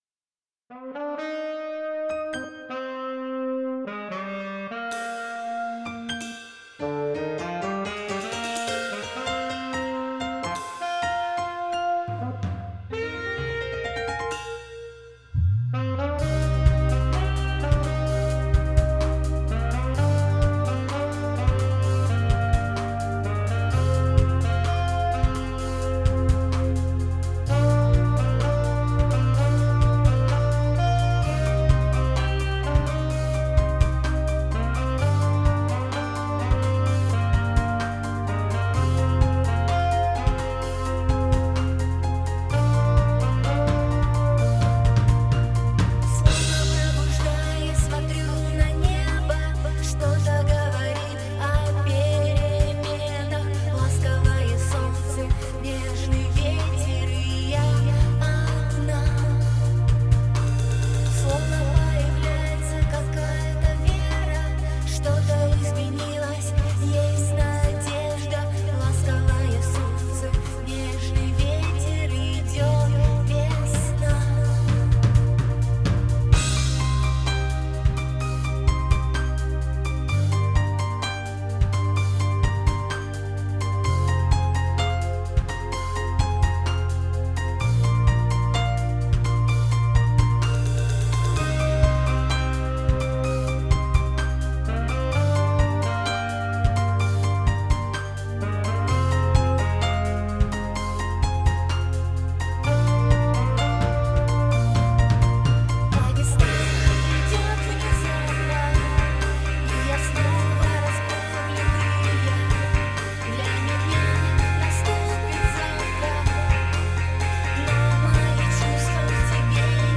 remix
Стиль: Rock